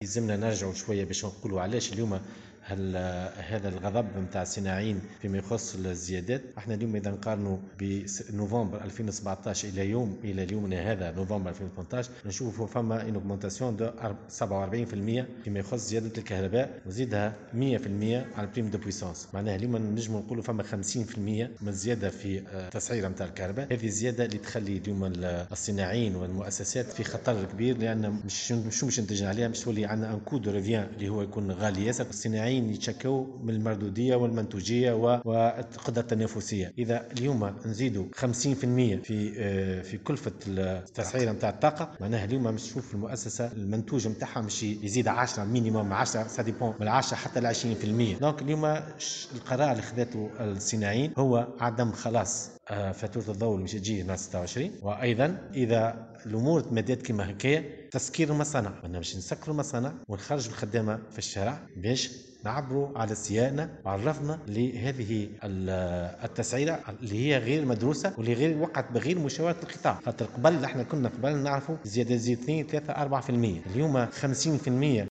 في تصريح لمراسل "الجوهرة أف أم"